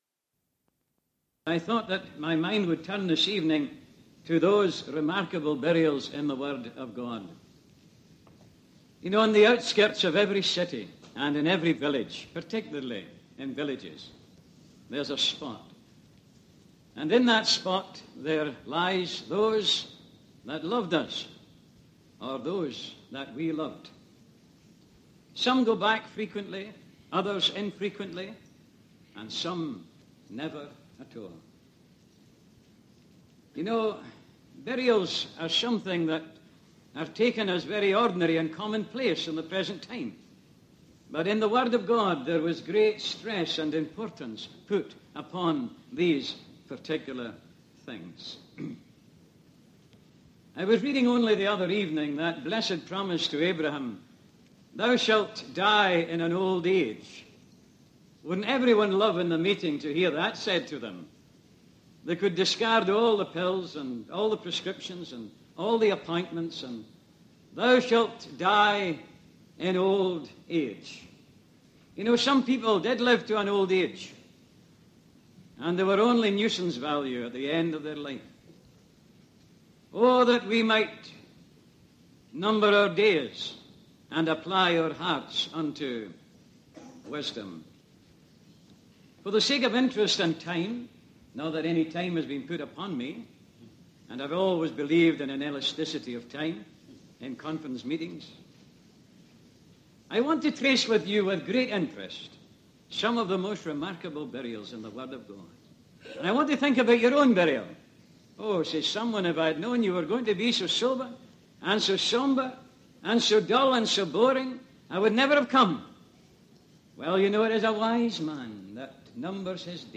(Recorded in England in the 1980's)
Historical Ministry Sermons